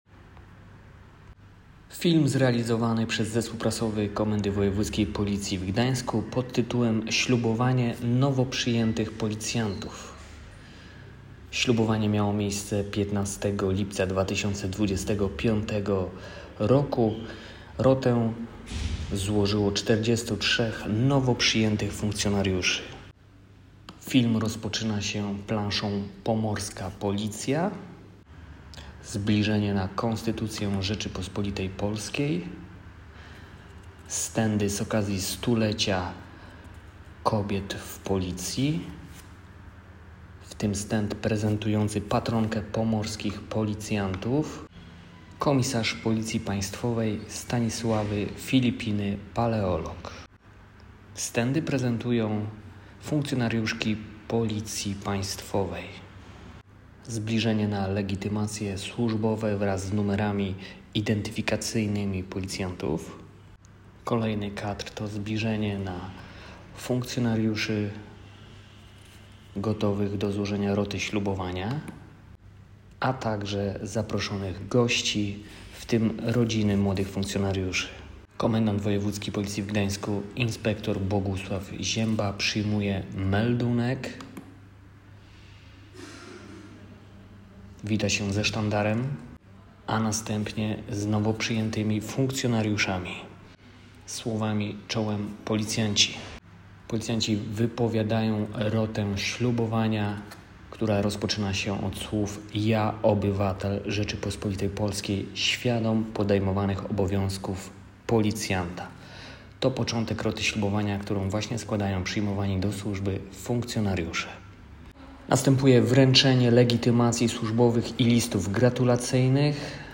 Ślubowanie nowo przyjętych policjantów
„Ja obywatel Rzeczypospolitej Polskiej, świadom podejmowanych obowiązków policjanta..." - to początek roty ślubowania, którą składają przyjmowani do służby funkcjonariusze, a którą przyjął dzisiaj Komendant Wojewódzkiego Policji w Gdańsku insp. Bogusław Ziemba.